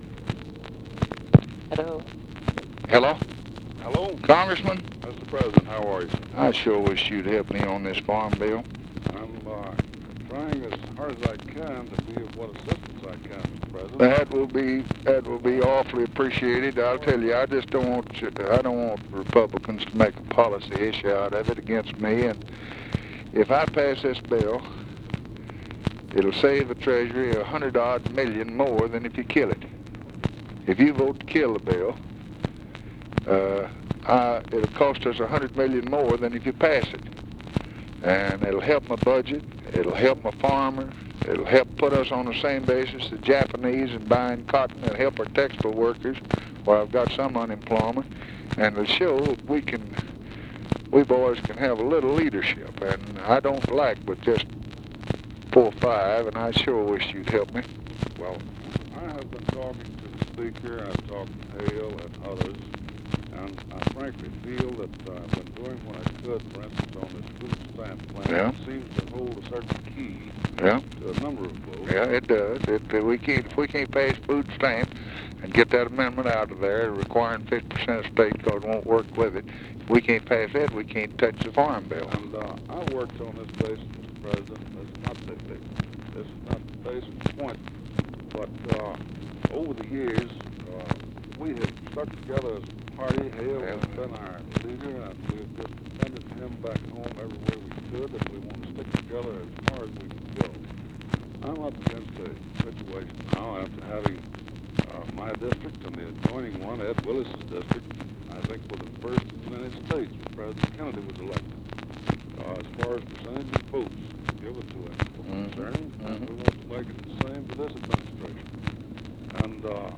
Conversation with T. ASHTON THOMPSON, April 7, 1964
Secret White House Tapes